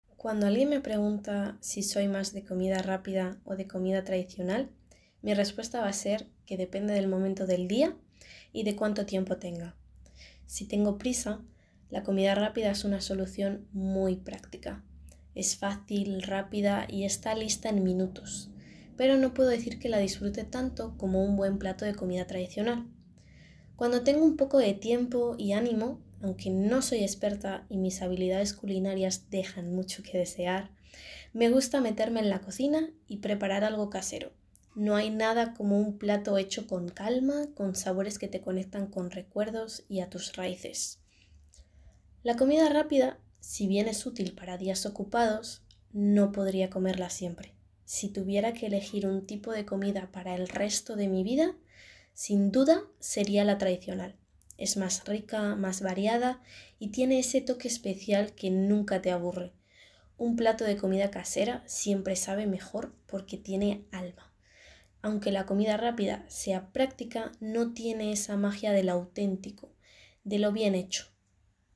Vous trouverez dans cette nouvelle rubrique de courts enregistrements réalisés par les assistants d’espagnol nommés dans notre académie, classés par thèmes et niveau du CECRL.